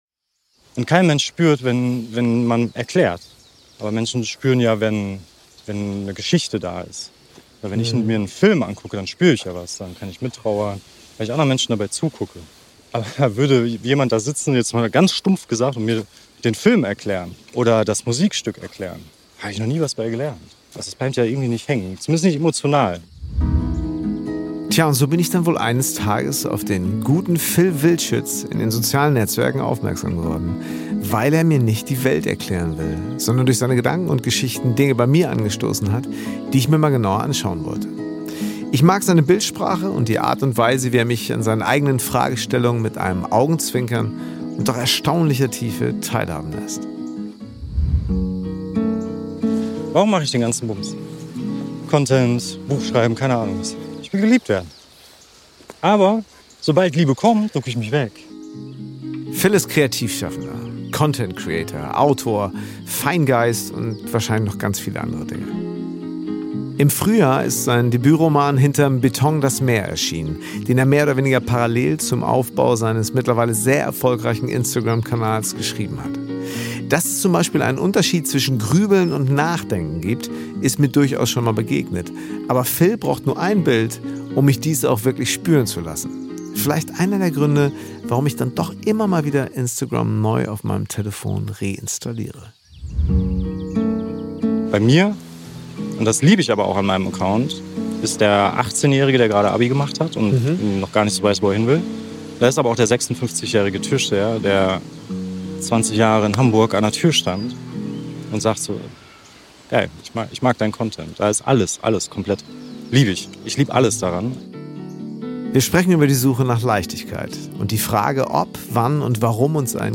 Wir sprechen über die Suche nach Leichtigkeit und die Frage, ob, wann und warum uns ein kreativer Alltag möglicherweise glücklicher macht. Ist das schon Coaching oder ganz einfach Lebenskunst - auch darum geht es in dieser Podcastfolge, die wir bereits im Mai bei einem herrlichen Waldspaziergang aufgenommen haben.